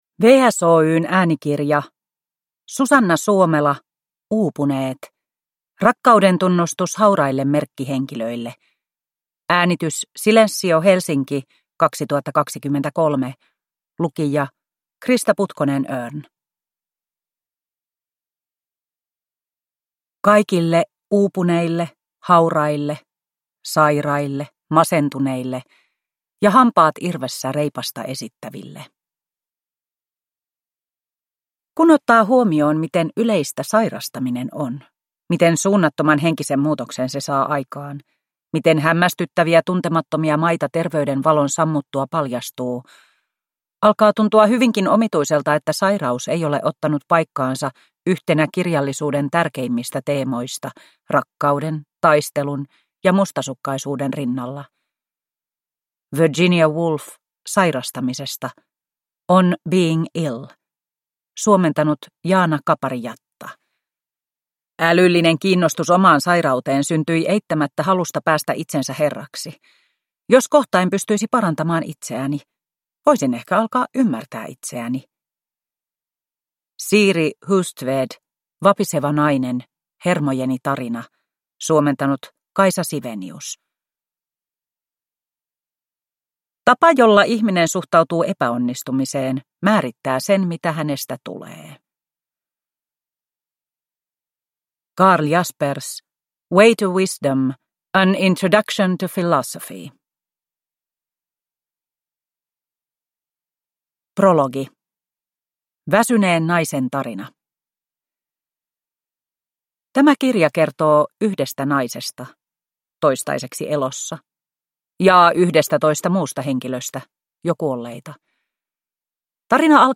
Uupuneet – Ljudbok